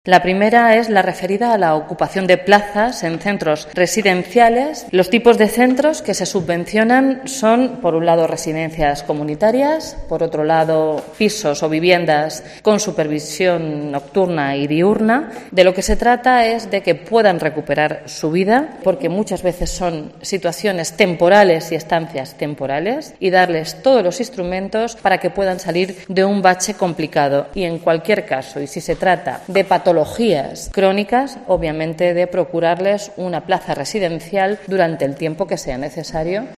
Blanca Fernández, portavoz Gobierno Castilla-La Mancha